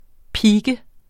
Udtale [ ˈpiːgə ]